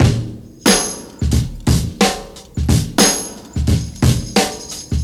95 Bpm Drum Loop Sample D# Key.wav
Free breakbeat sample - kick tuned to the D# note.
95-bpm-drum-loop-sample-d-sharp-key-bmR.ogg